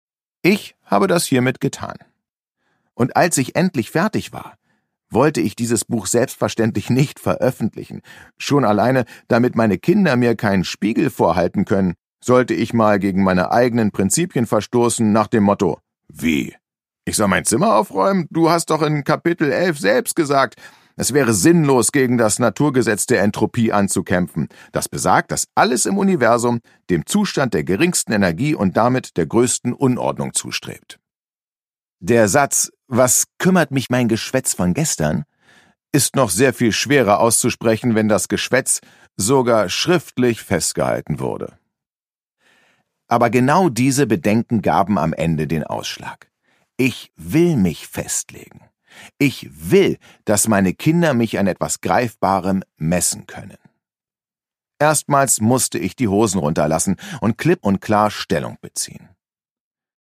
Produkttyp: Hörbuch-Download
Gelesen von: Sebastian Fitzek